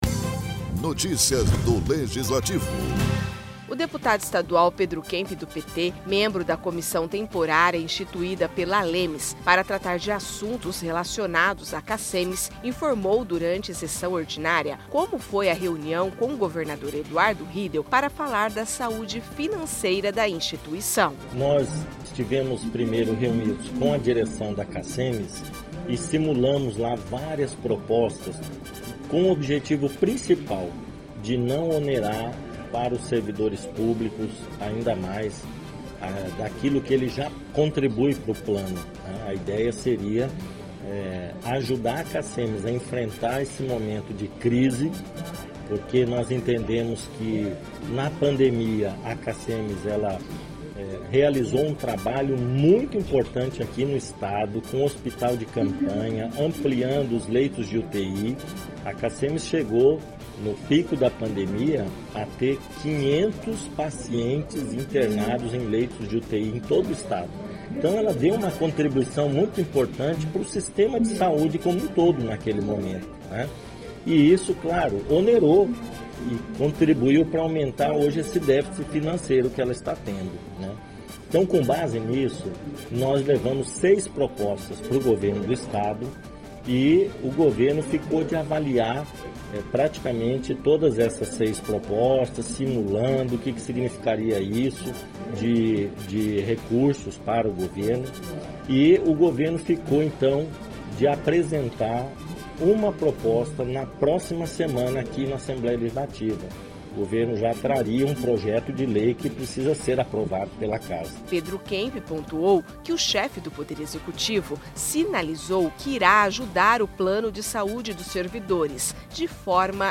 O deputado estadual Pedro Kemp (PT), membro da Comissão Temporária instituída pela Assembleia Legislativa de Mato Grosso do Sul (ALEMS) para tratar de assuntos relacionados à Cassems, informou durante sessão ordinária, como foi à reunião com governador Eduardo Riedel, para tratar da saúde financeira da instituição.